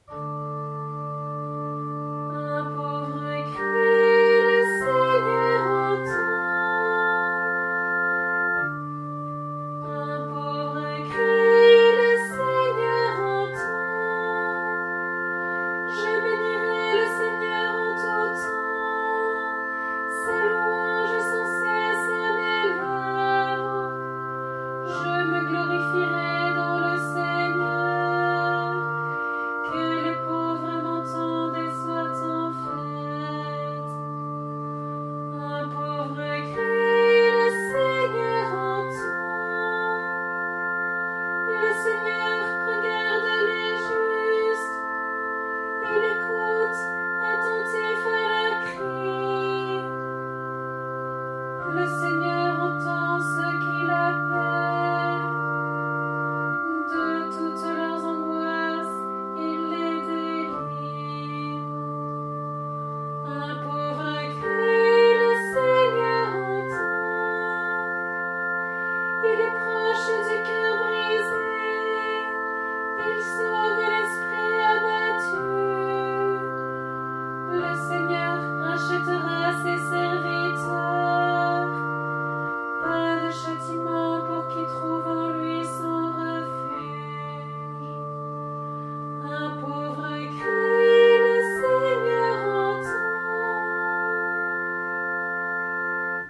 Antienne pour le 30e dimanche du Temps Ordinaire (année C)